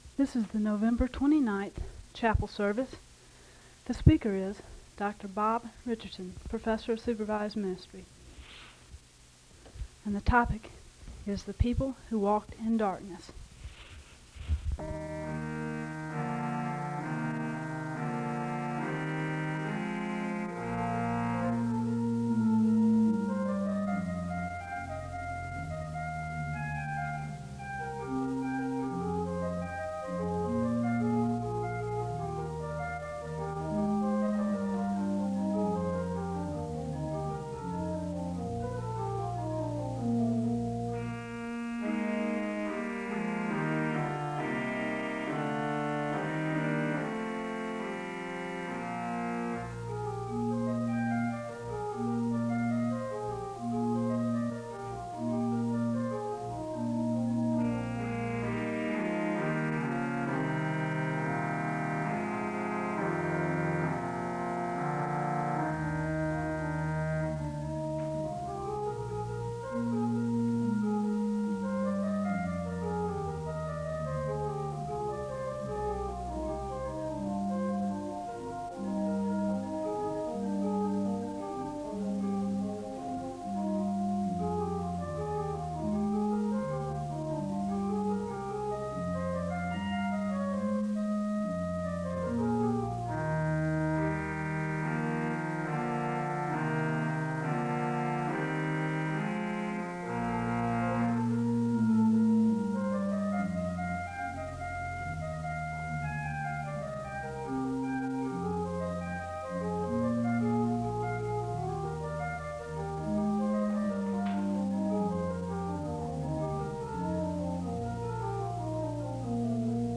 The service begins with organ music (00:00-04:30).
The service ends with a benediction (24:40-24:55).